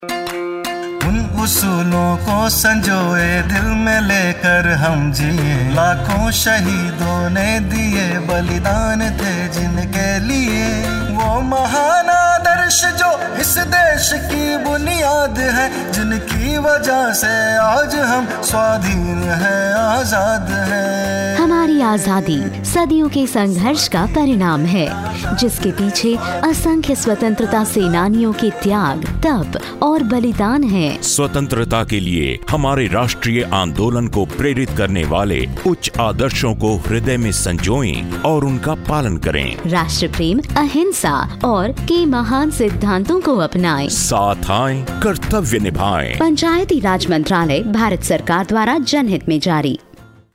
Radio Jingle